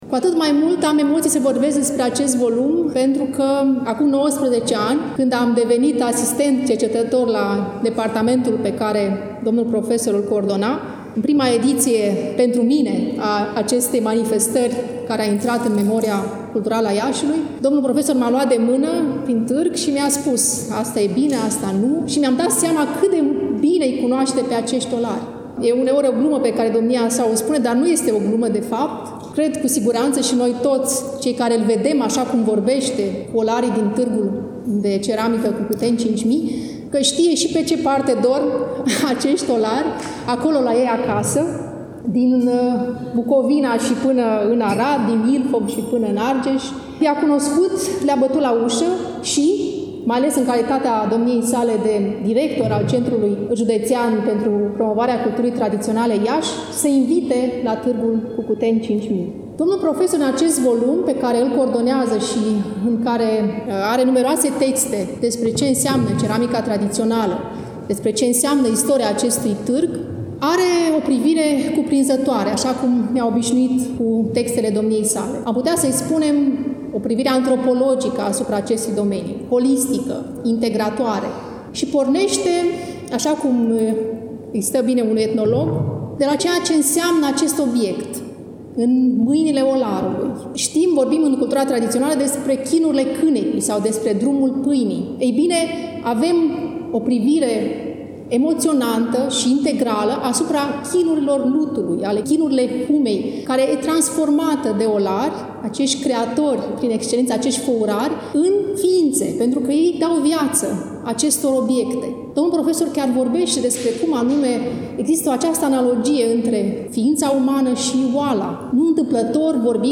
Volumul a fost lansat, la Iași, nu demult, în Sala „Petru Caraman” din incinta Muzeului Etnografic al Moldovei, Palatul Culturii.